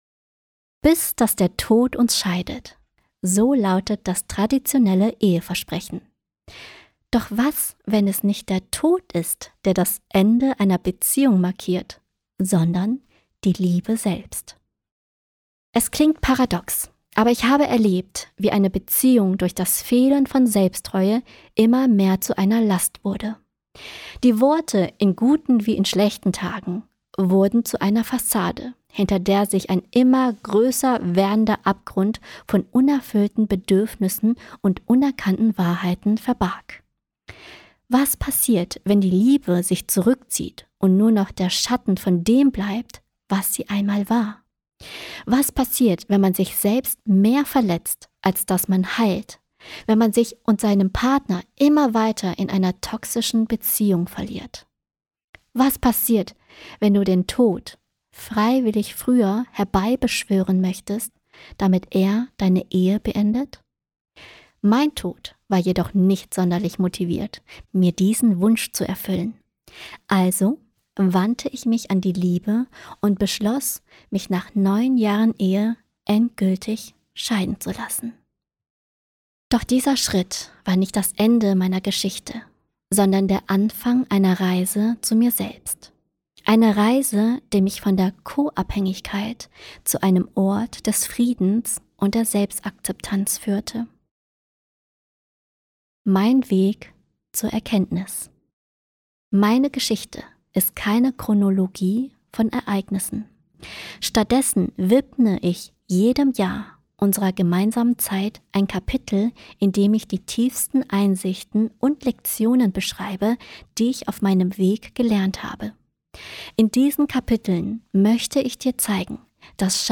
Bis-dass-die-Liebe-uns-scheidet-Hoerprobe.mp3